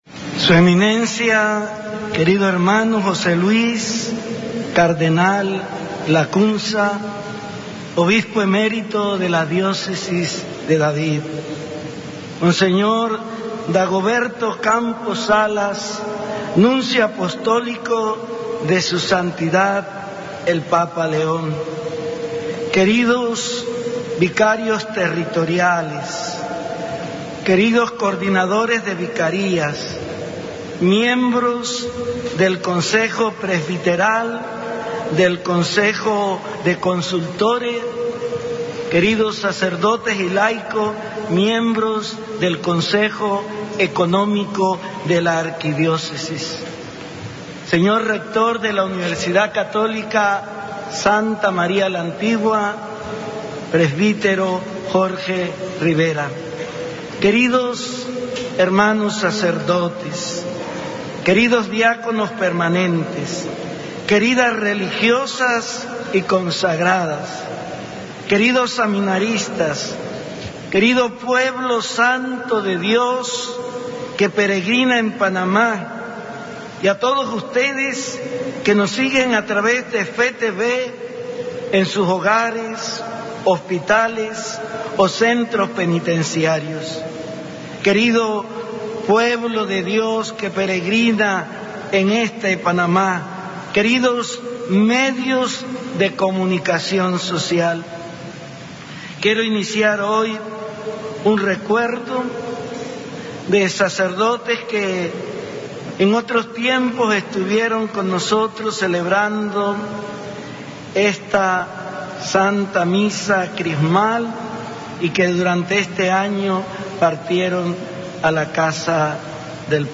HOMILÍA DE MISA CRISMAL Y APERTURA DEL AÑO JUBILAR 1925–2025 DE LA ARQUIDIÓCESIS DE PANAMÁ - Arquidiócesis de Panamá
Mons. José Domingo Ulloa Mendieta OSA Catedral Basílica Santa María la Antigua, 31 de marzo de 2026